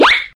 slip.wav